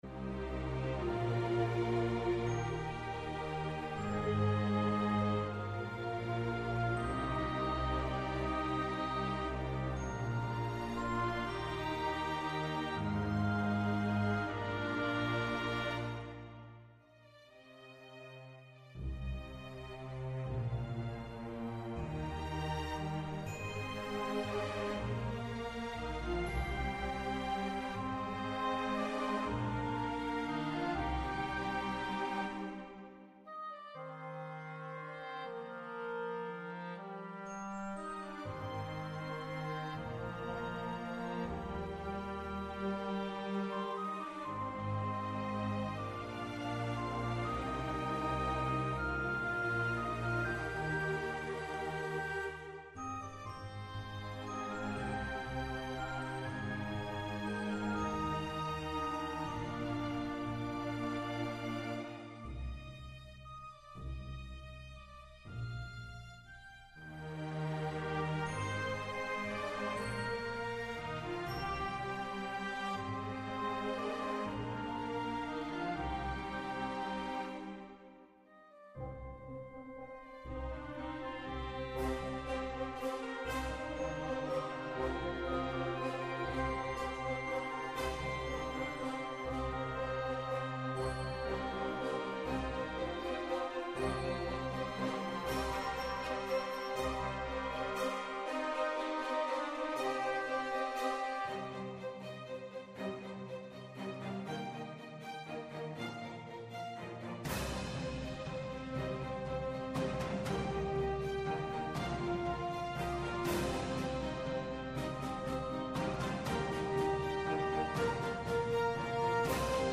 ΔΕΥΤΕΡΟ ΠΡΟΓΡΑΜΜΑ Ροκ Συναναστροφες Ροκ Συναναστροφες Αφιερώματα Μουσική Συνεντεύξεις